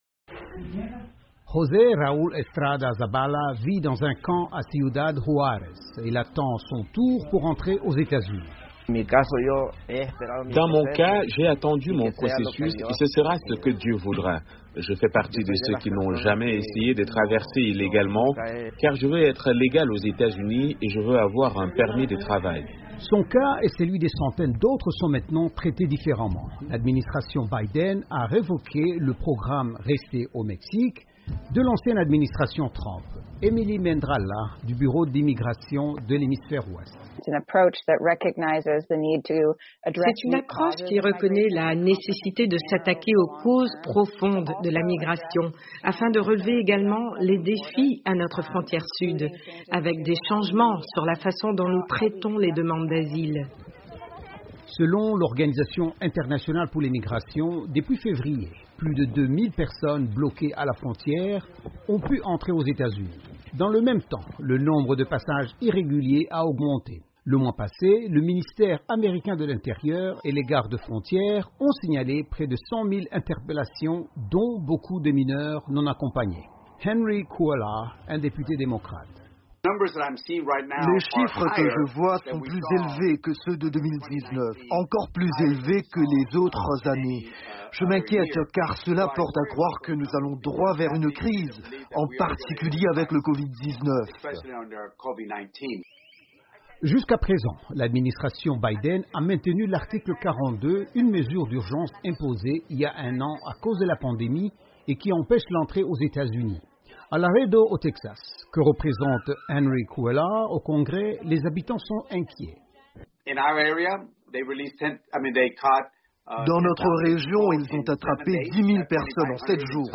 Des milliers sont massés à la frontière sud dans l'espoir d'entrer pour réaliser le "rêve américain". Un reportage